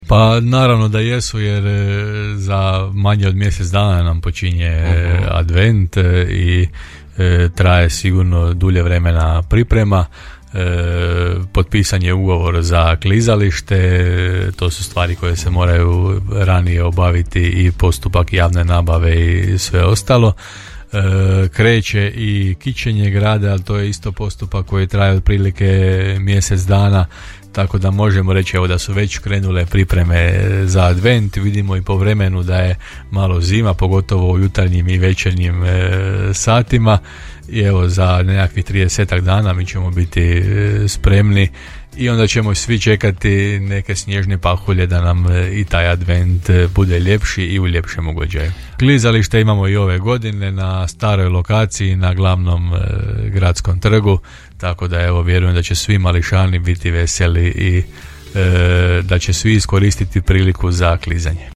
Postavljanje dekoracija i lampica već je krenulo, a najmlađi će i ove godine u blagdansko vrijeme uživati na klizalištu. Potvrdio nam je to gradonačelnik Grada Đurđevca Hrvoje Janči u emisiji Gradske teme;